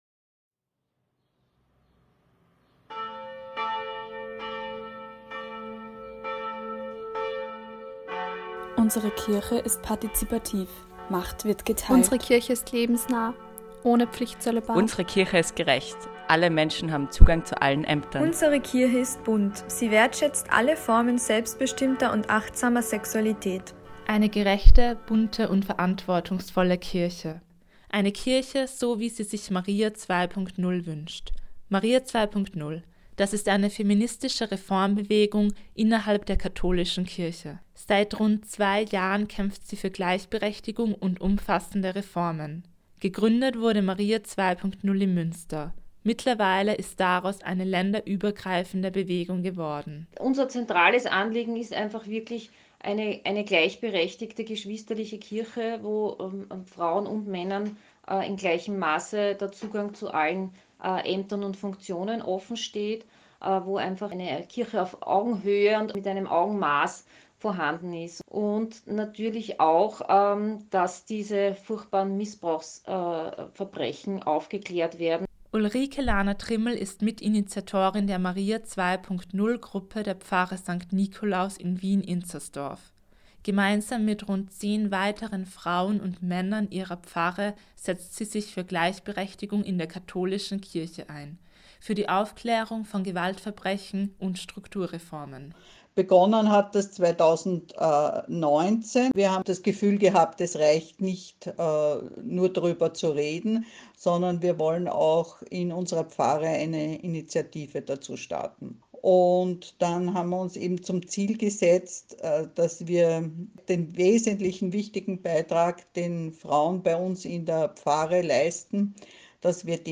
Sender der FH Wien ausgestrahlt wurde, vom Mai 2021